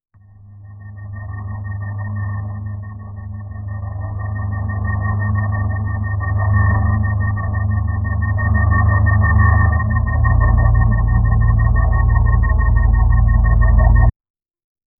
futuristic building atmospheric electronic sound
futuristic-building-atmos-t4cquym6.wav